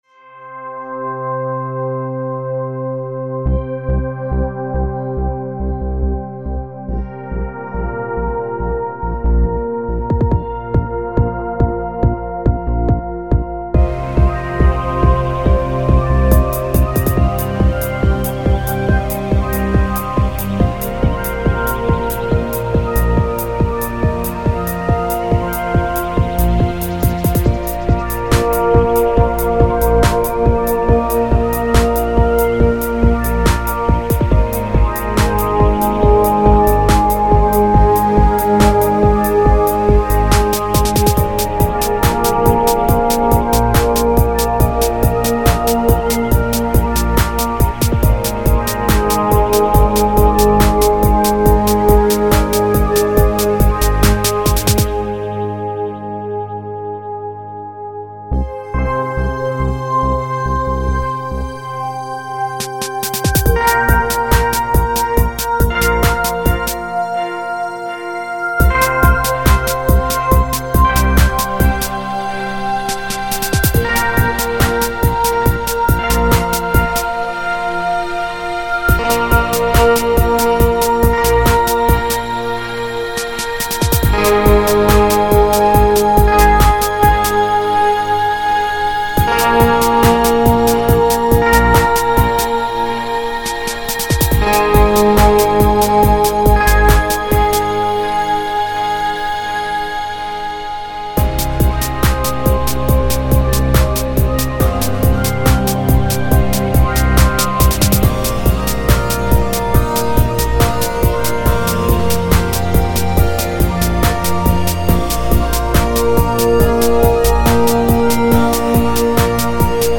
mostly a techno sound